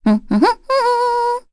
Cecilia-Vox_Hum_kr.wav